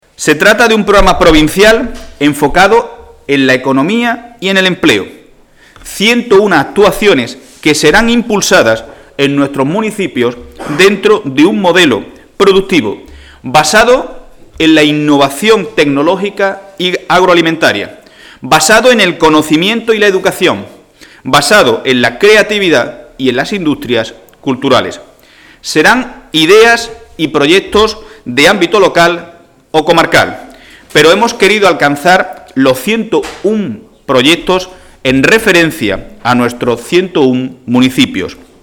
Nosotros, no", ha señalado el secretario general del PSOE malagueño, Miguel Ángel Heredia en rueda de prensa.